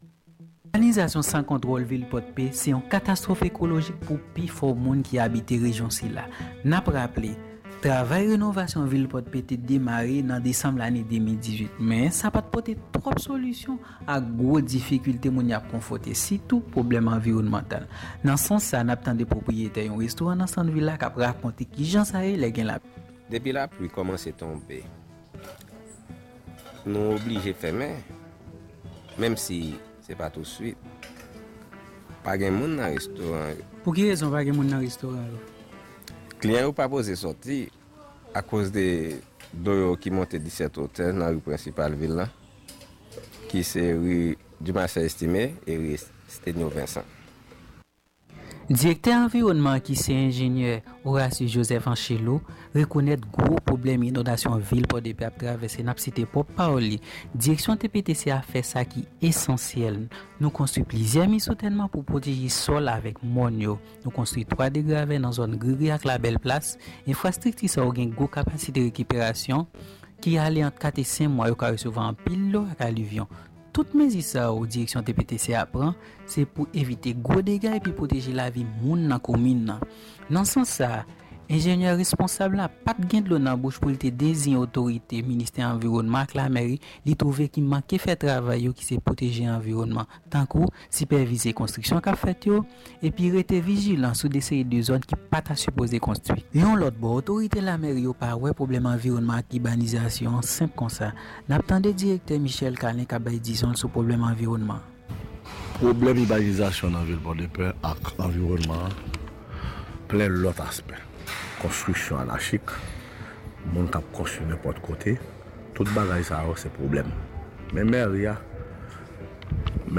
Repotaj sou ibanizasyon vil Pòdpe, yon danje ekolojik pou moun kap viv nan zòn sa a_
Repotaj-sou-ibanizasyon-vil-Podpe-yon-danje-ekolojik-pou-moun-kap-viv-nan-zon-sa-a_.mp3